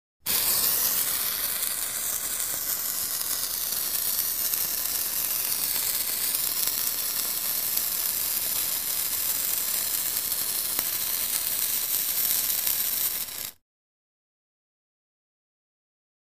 Fuse Burn; Short Sputtering Fuse.